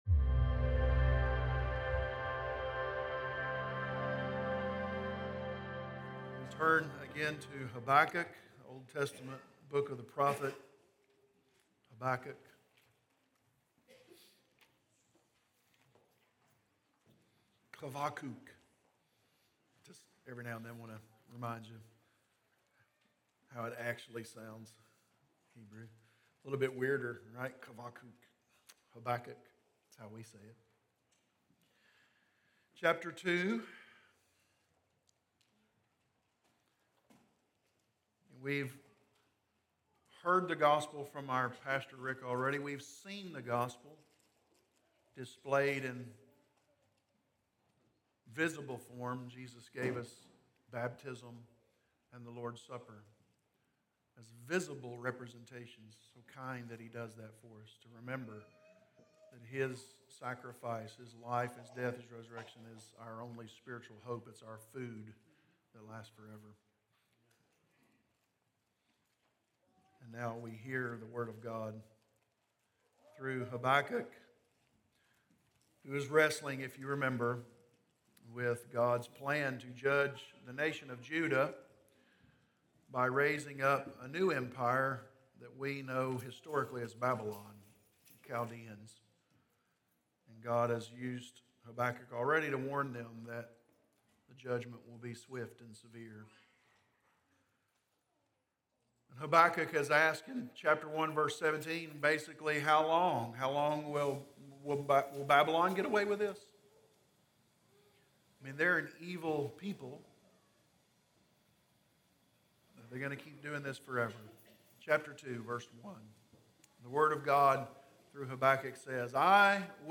Explore other Sermon Series
Sermons recorded during the Sunday morning service at Corydon Baptist Church in Corydon, Indiana